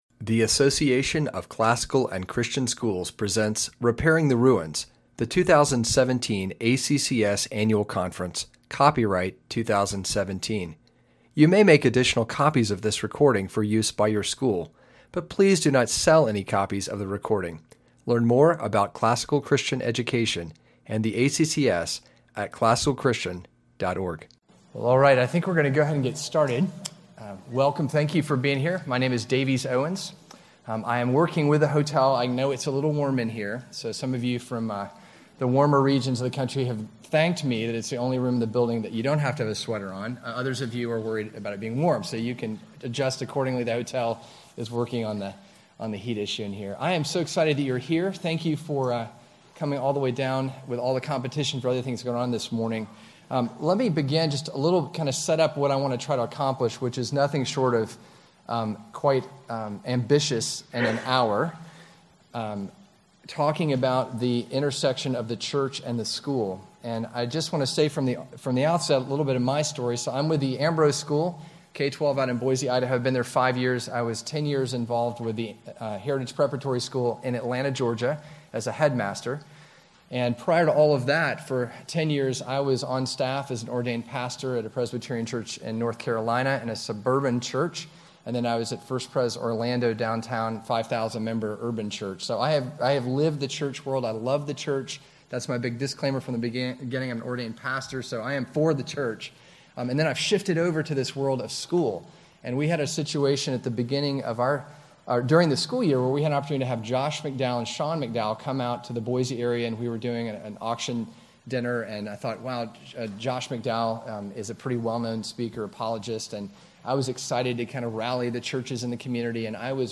2017 Foundations Talk | 1:03:03 | All Grade Levels, Culture & Faith, Bible & Theology